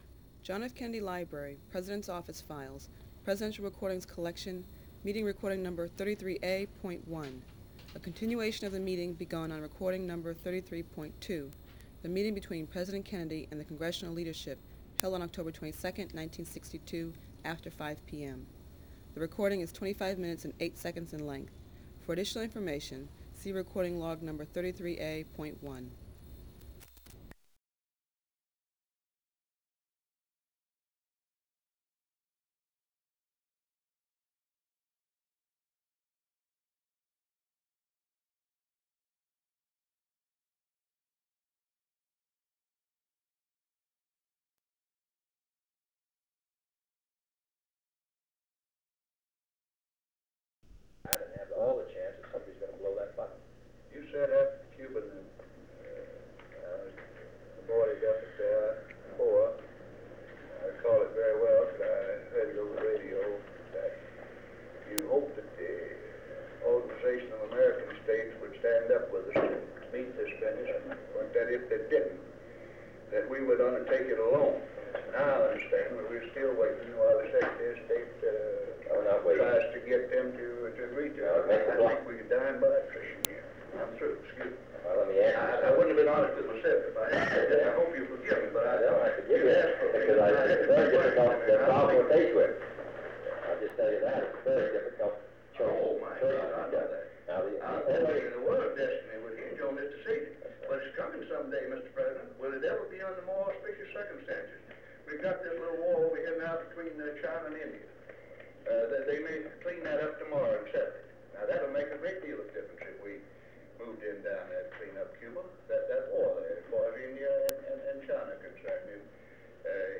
Secret White House Tapes | John F. Kennedy Presidency Meeting with the Congressional Leadership on the Cuban Missile Crisis (cont.)